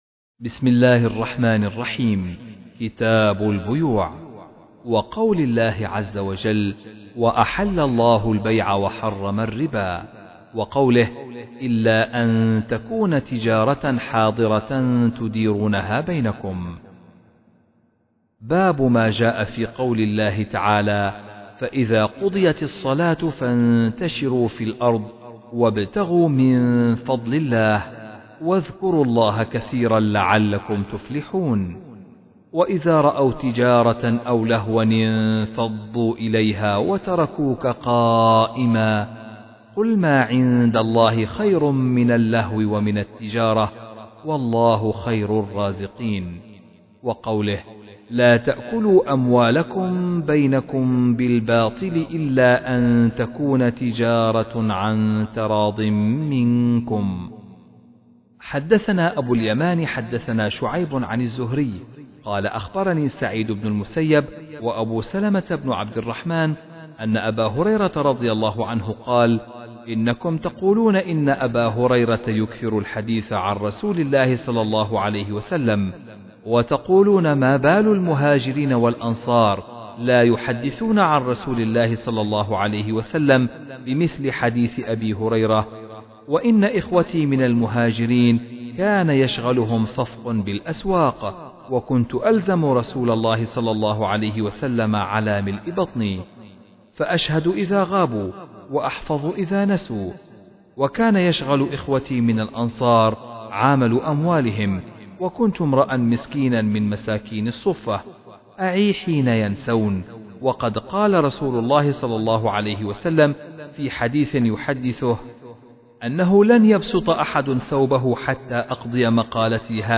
كتاب البيوع - قراءة من كتاب صحيح الامام البخاري - قسم المنوعات